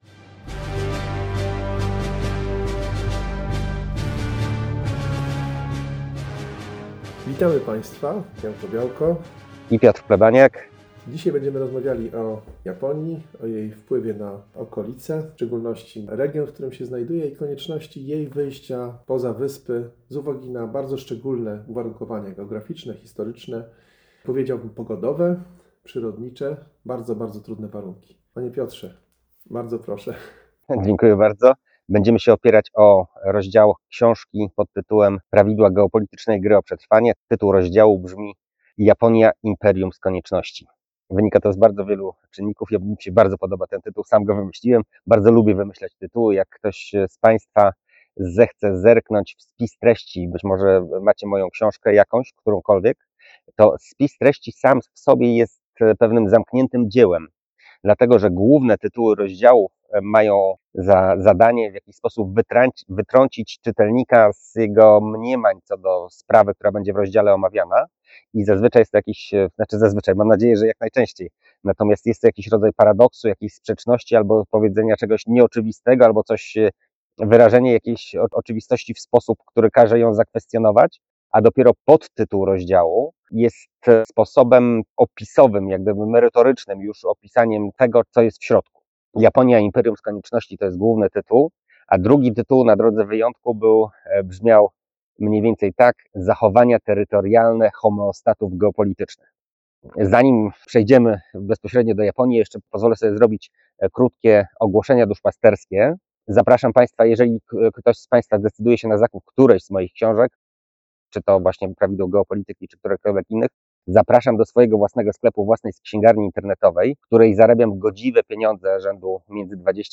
Główna omawiana książka: Prawidła geopolitycznej gry o przetrwanie Nasza przyszłość w czasach niepewności Prezentacja Kup od autora Pocztówka z Tajwanu Miejsce realizacji nagrania Pola ryżowe powiatu Haiduan, obszaru, który razem z powiatem Fuli jest ikonicznym miejscem uprawy ryżu na Tajwanie.